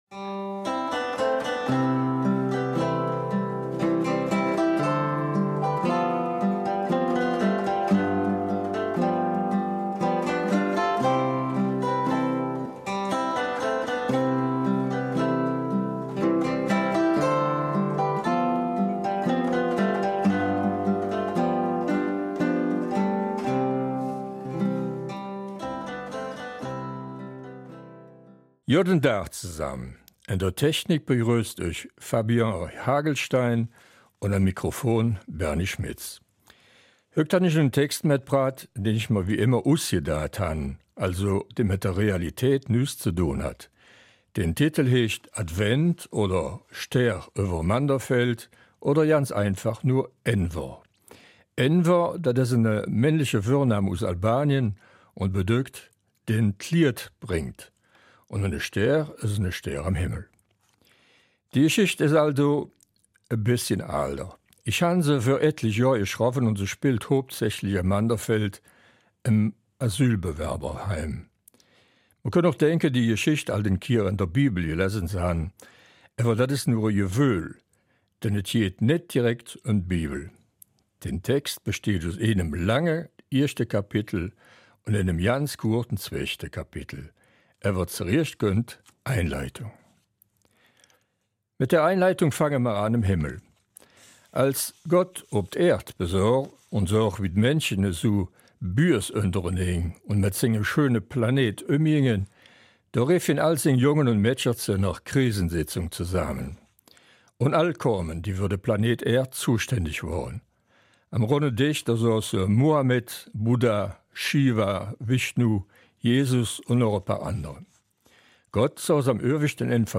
Eifeler Mundart - 18. Dezember